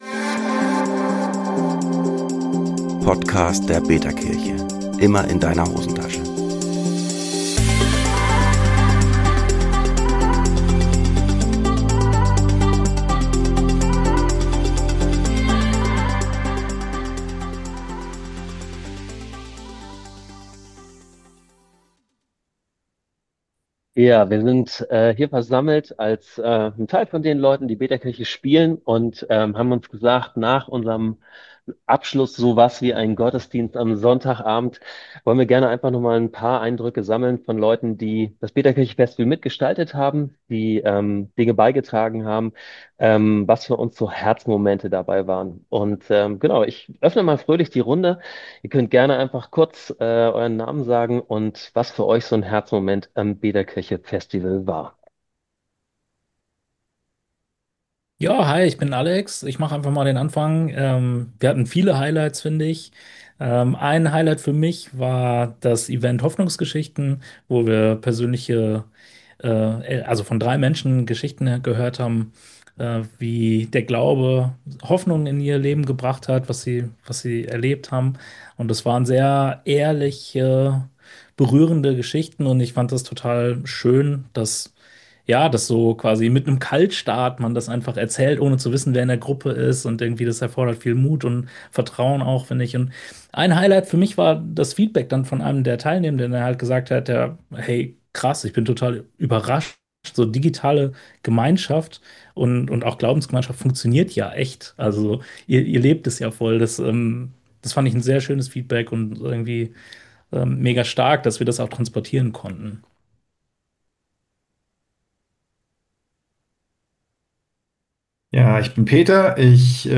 Stimmen direkt aus unserer digitalen Gemeinde – live mitgeschnitten vom Abschluss unseres betaKirche Festivals.